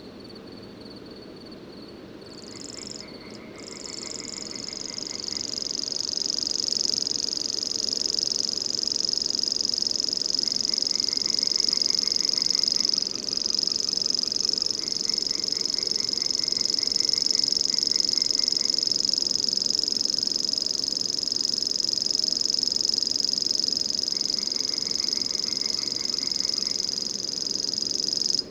Feldschwirl.wav